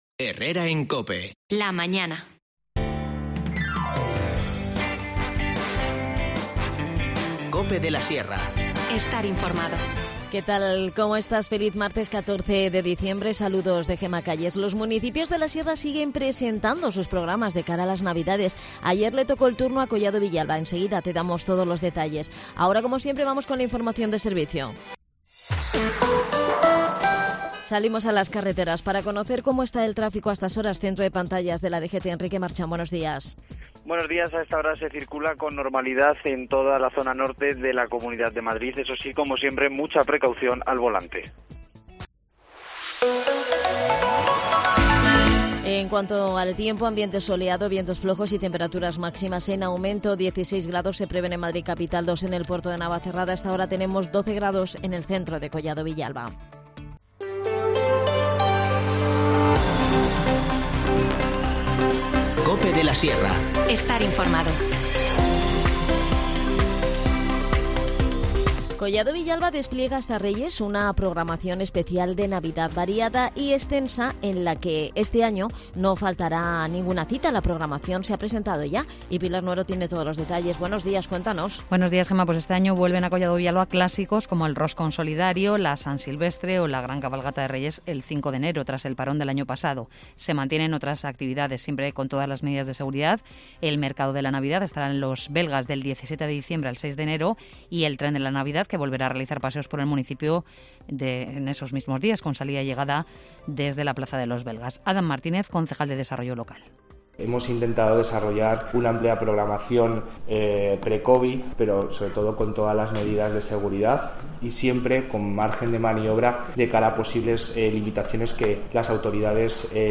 Charlamos con ella en el programa.